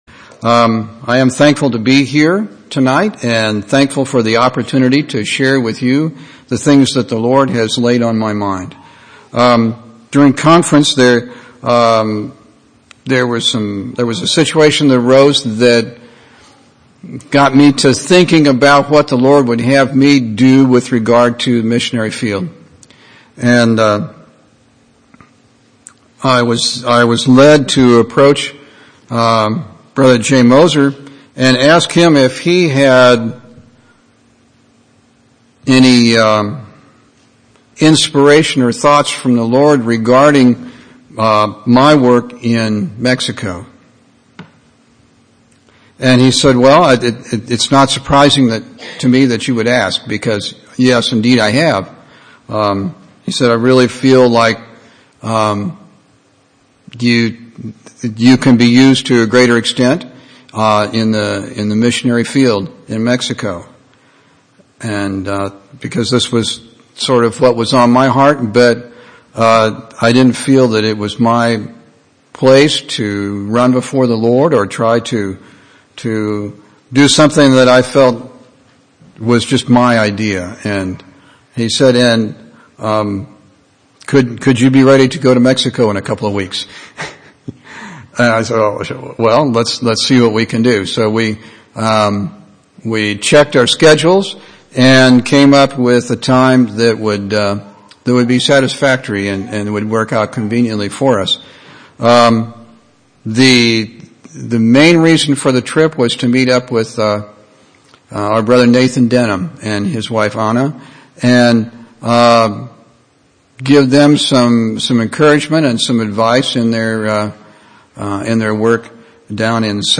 5/10/2009 Location: Temple Lot Local Event